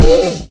sounds / mob / horse / zombie / hit2.mp3